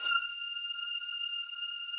Updated string samples
strings_077.wav